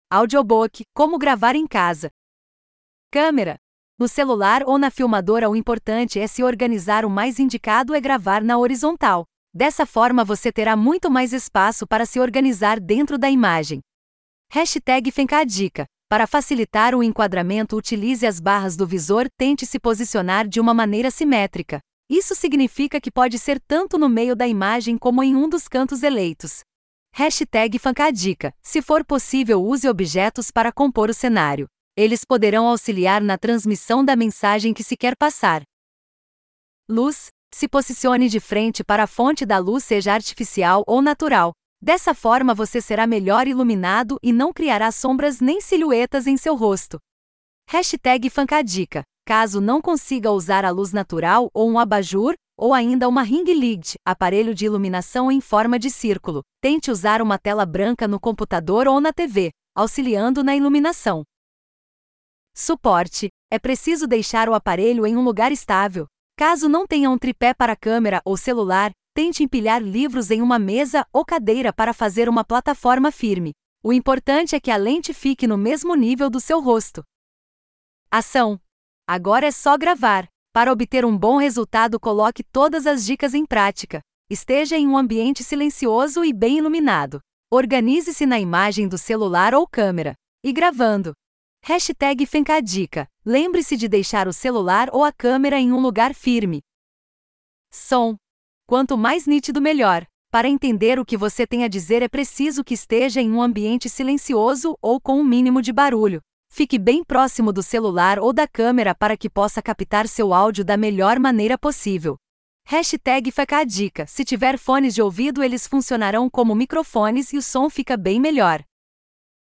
Audiobook-como-gravar-em-casa.mp3